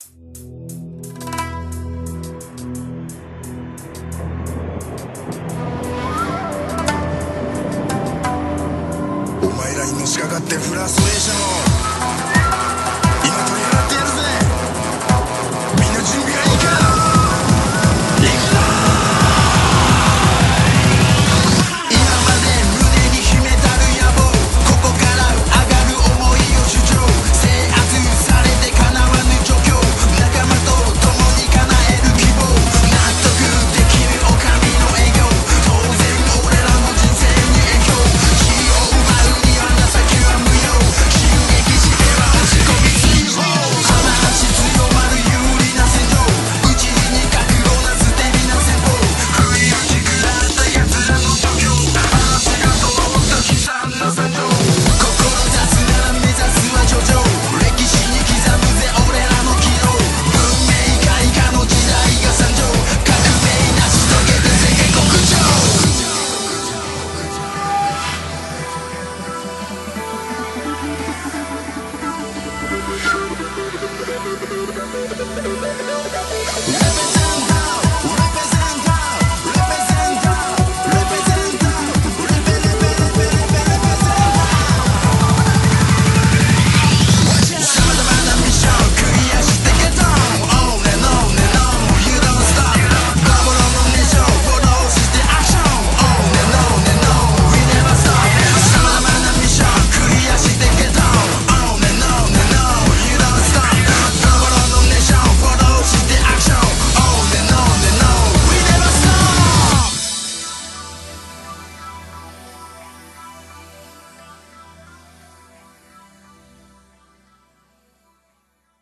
BPM175
Audio QualityMusic Cut